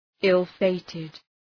{,ıl’feıtıd}